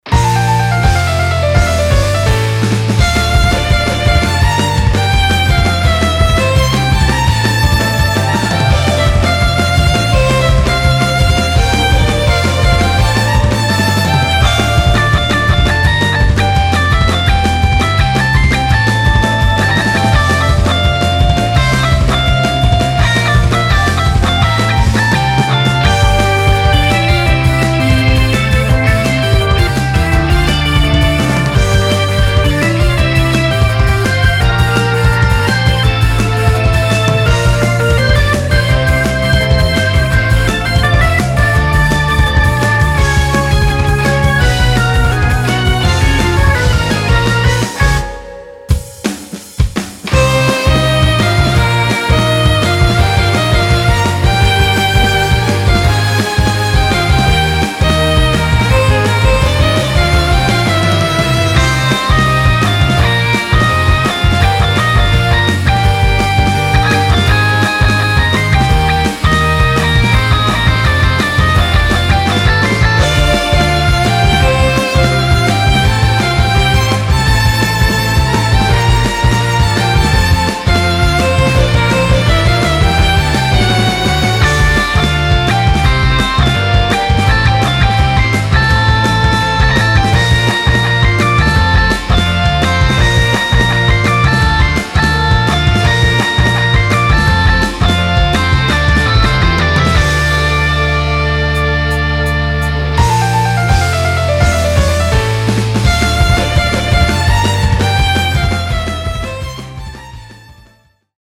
フリーBGM バトル・戦闘 バンドサウンド
フェードアウト版のmp3を、こちらのページにて無料で配布しています。